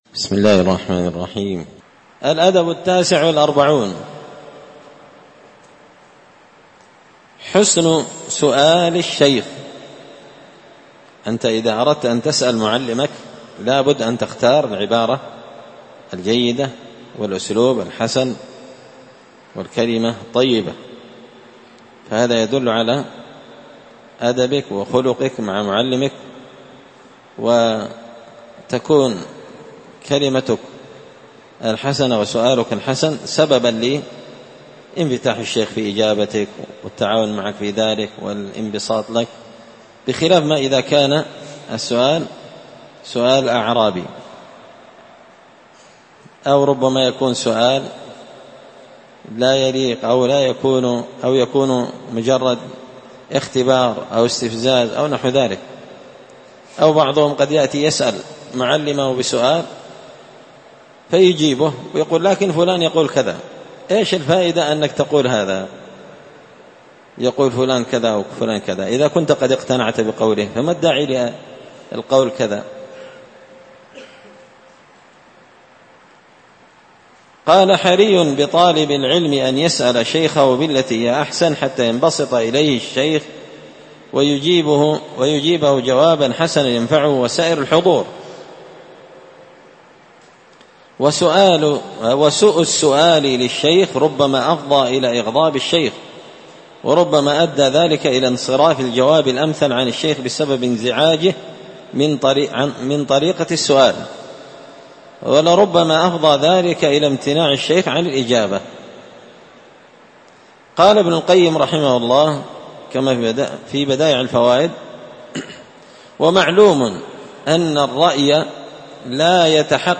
الدرس السابع والخمسون (57) الأدب التاسع والأربعون حسن سؤال الشيخ